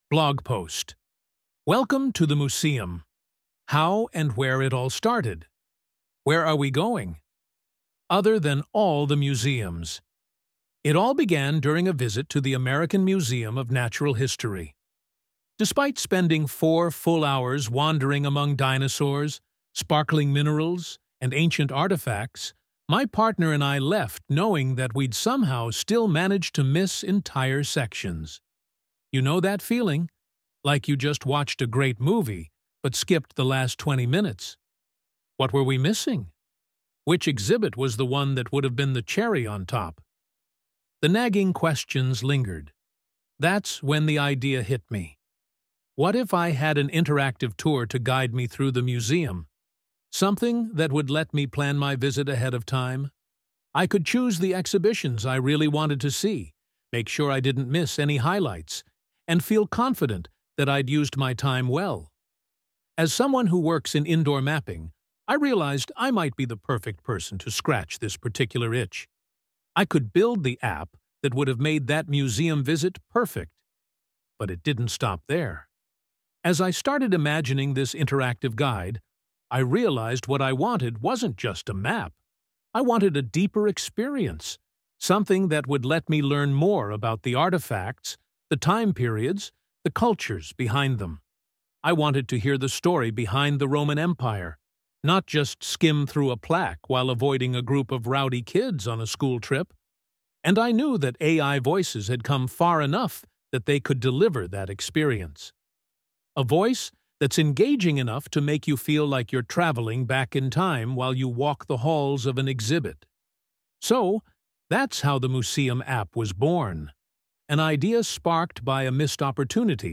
Blog audio as read by one of our AI narrators.